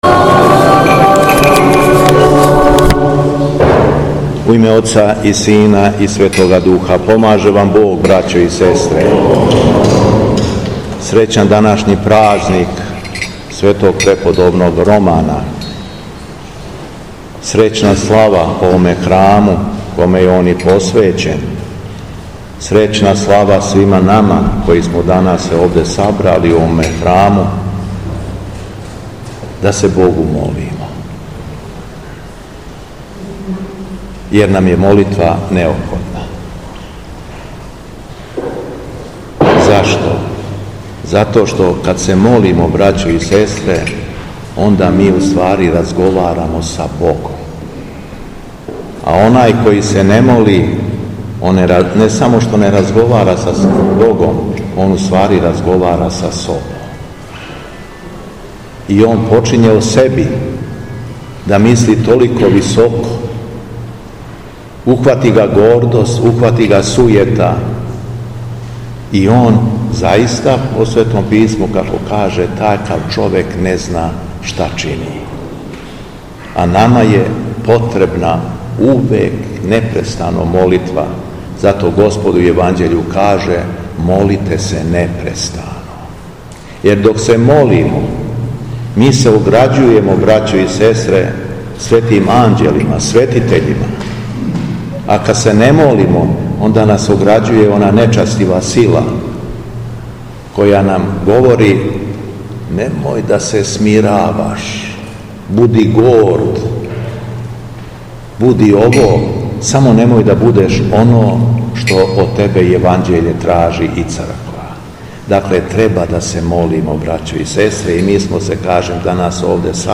Беседа Његовог Високопреосвештенства Митрополита шумадијског г. Јована
Митрополит се окупљеним верицима обратио надахнутом беседом рекавши: